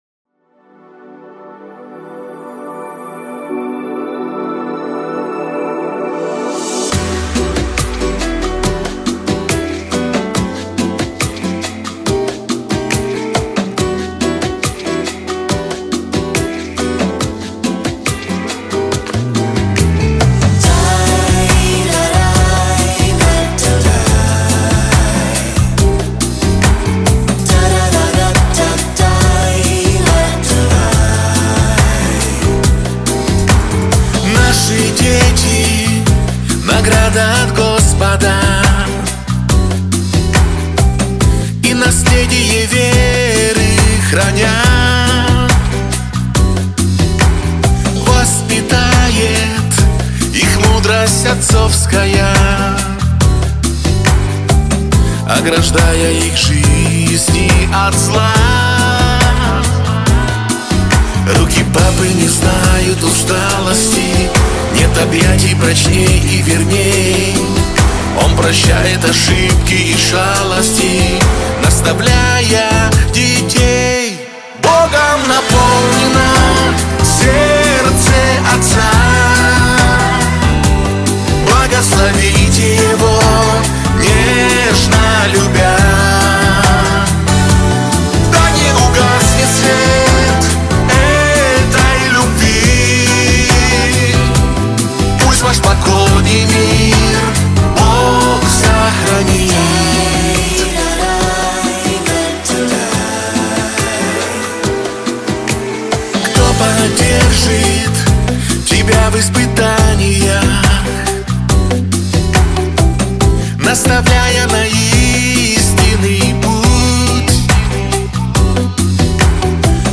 песня
98 просмотров 89 прослушиваний 17 скачиваний BPM: 143